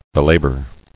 [be·la·bor]